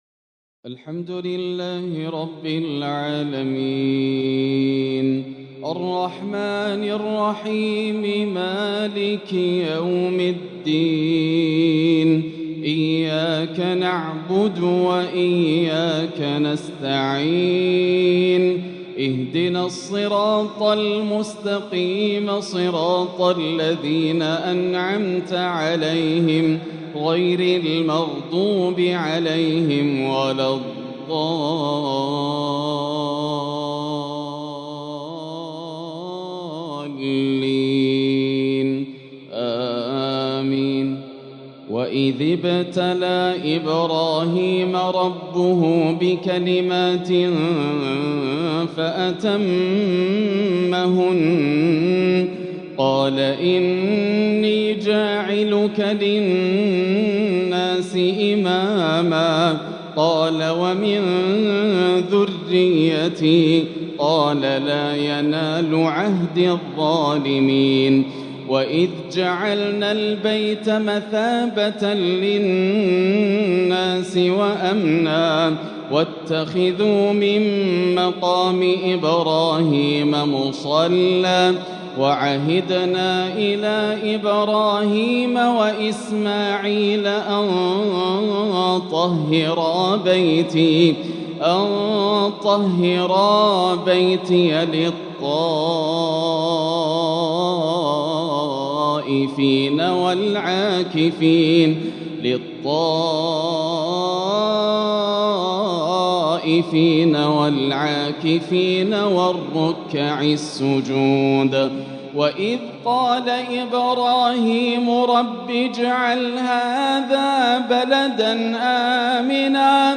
تلاوة هادئة تطمئن لها القلوب للآسر د. ياسر الدوسري | ١٢ ذو الحجة ١٤٤٤هـ > تلاوات عام 1444هـ > مزامير الفرقان > المزيد - تلاوات الحرمين